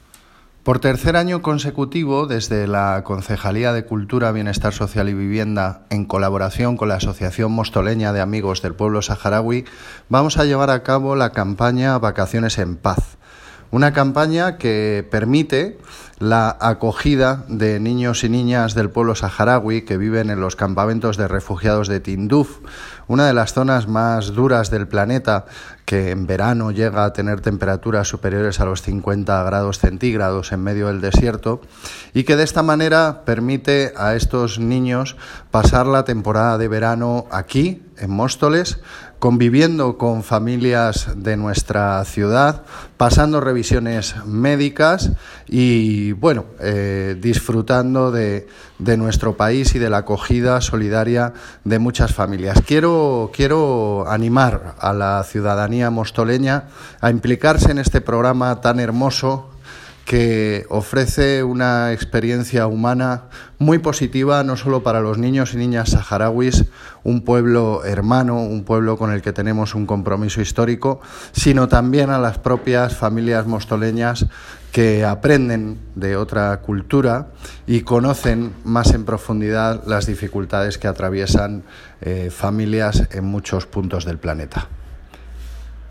Audio - Gabriel Ortega (Concejal de Cultura, Bienestar Social y Vivienda) Sobre Vacaciones en Paz